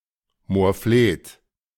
Moorfleet (German pronunciation: [moːɐ̯ˈfleːt]
De-Moorfleet.ogg.mp3